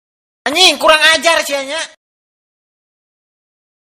Efek suara Kurang ajar sia nya
Kategori: Suara viral
efek-suara-kurang-ajar-sia-nya-id-www_tiengdong_com.mp3